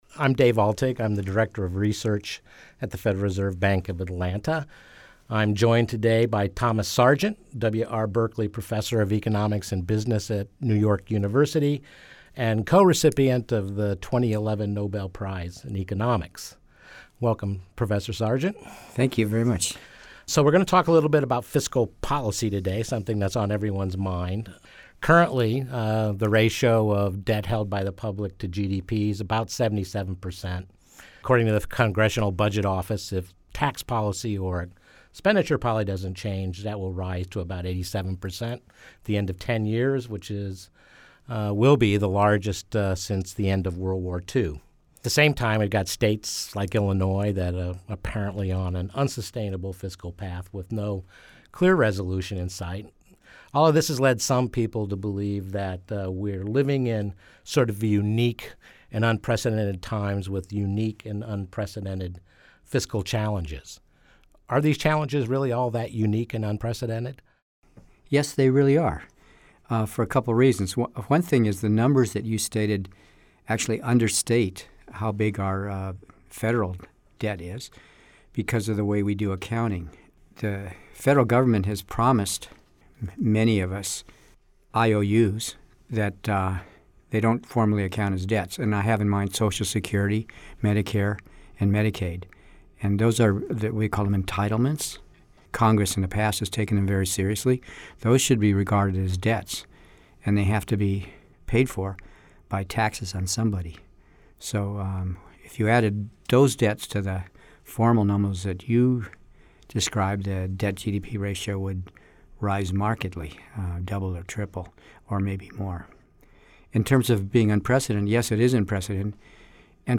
Questions surrounding the power to tax and spend have hugely influenced world and American history, dating to the country's founding, Nobel Prize-winning economist Thomas Sargent of New York University explained on May 16 at the Federal Reserve Bank of Atlanta's headquarters. Speaking at a Public Affairs Forum held during the Workshop on Monetary and Financial History, Sargent described how conflicting interests in fiscal policy—primarily tensions between government bond holders wishing to be repaid and taxpayers who must finance those bonds—have shaped alliances, constitutions, and even revolutions.